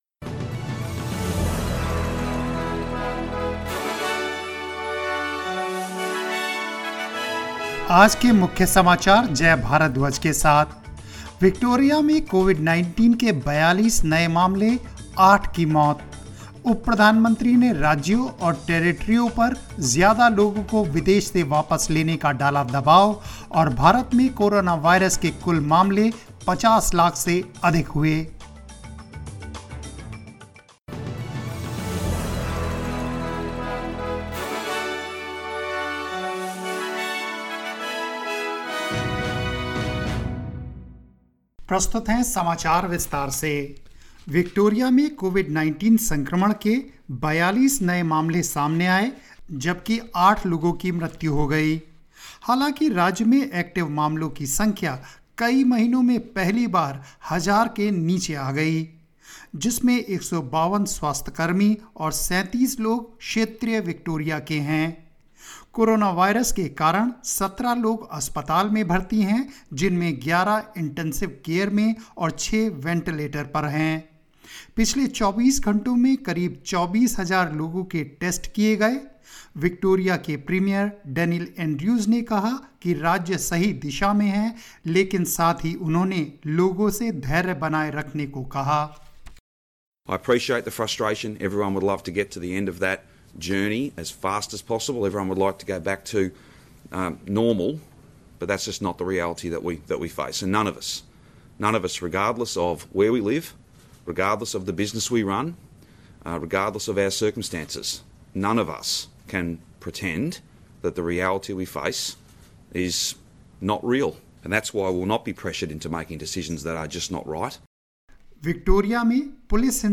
News in Hindi 16 September 2020